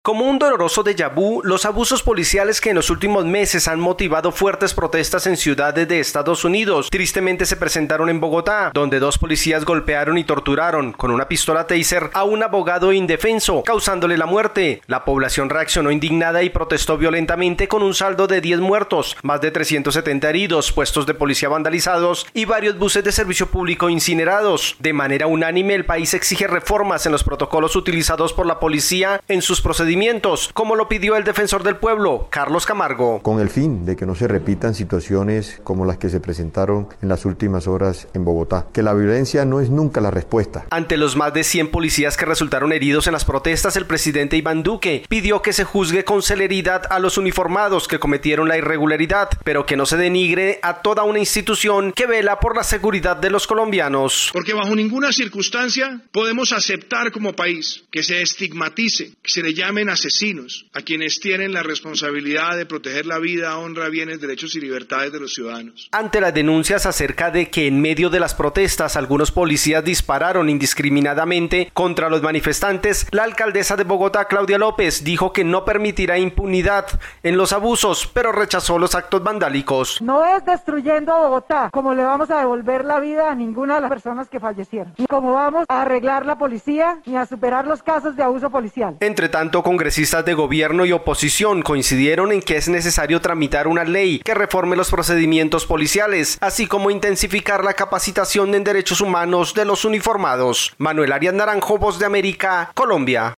La ciudadanía en Colombia exige rotundos cambios en los procedimientos policiales mientras las autoridades rechazaron las violentas protestas en Bogotá. Desde Colombia informa el corresponsal de la Voz de América